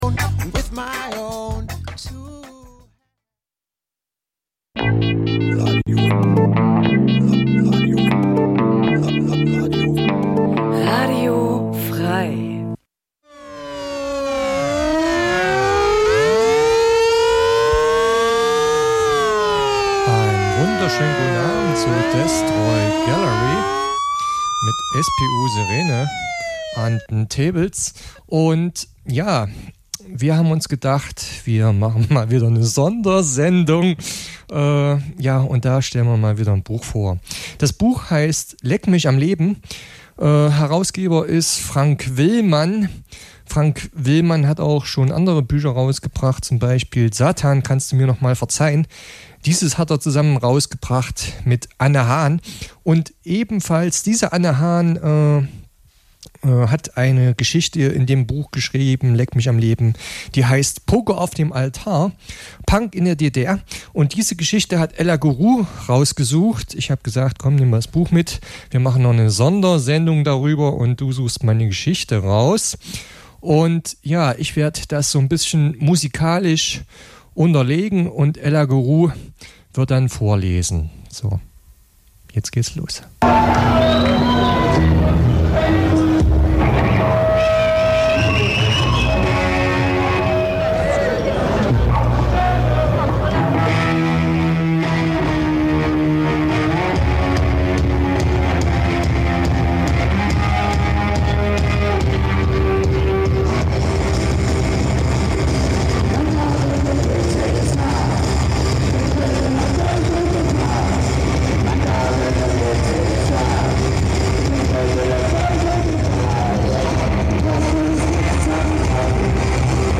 Punk '77 - '79 Dein Browser kann kein HTML5-Audio.